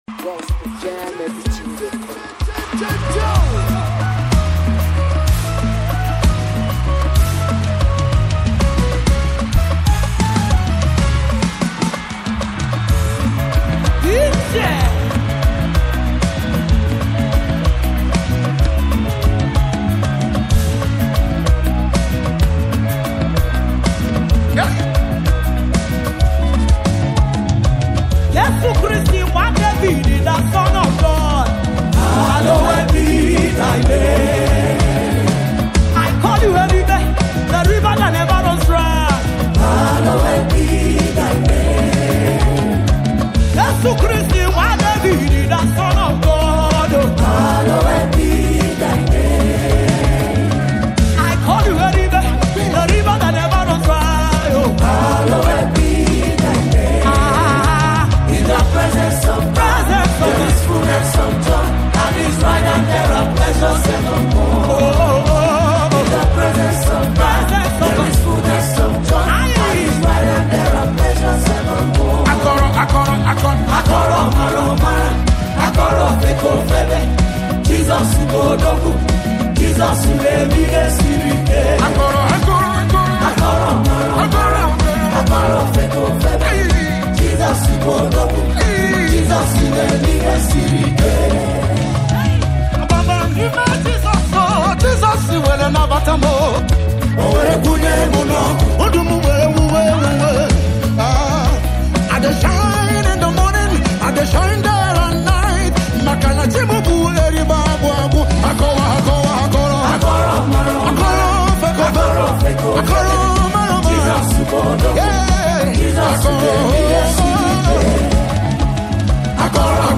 With strong vocals and spirit-filled delivery